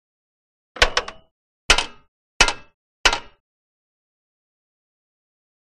Motorcycle; Kick Stand; Motorbike Stand Down And Up.